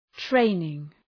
Προφορά
{‘treınıŋ}